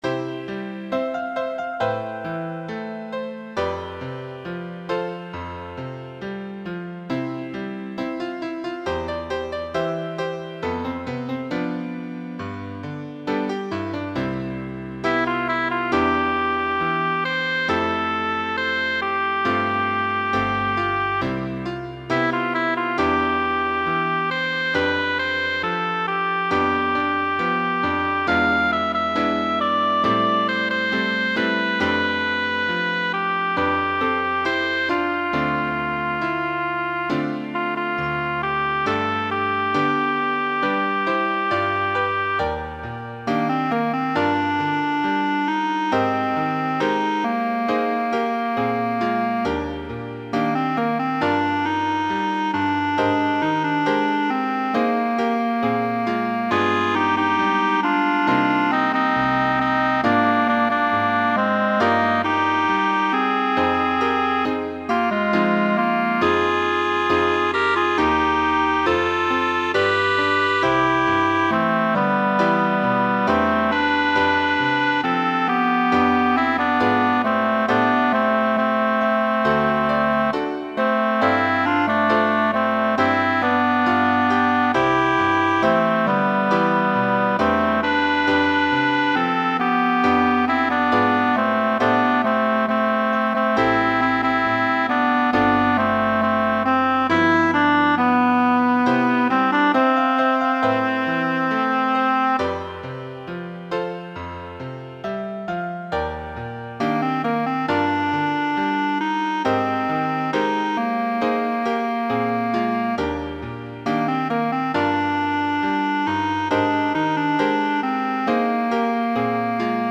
Voicing SATB Instrumental piano Genre Broadway/Film
Function Ballad